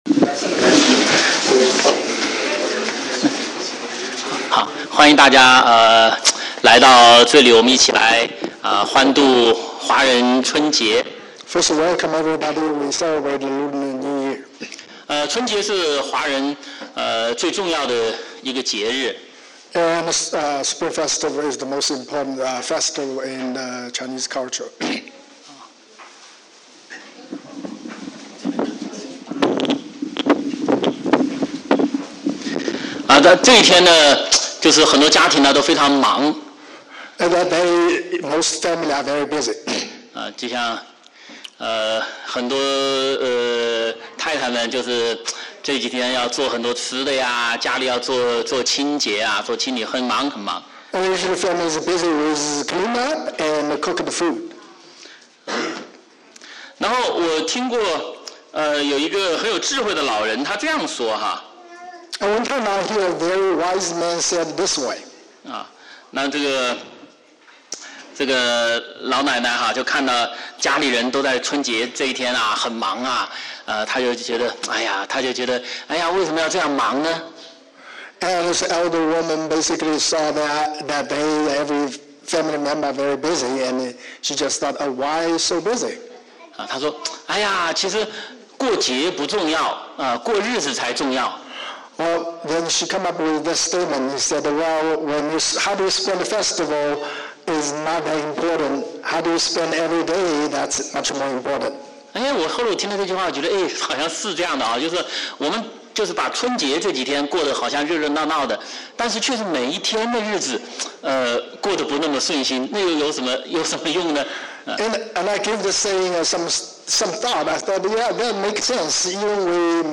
周五晚上查经讲道录音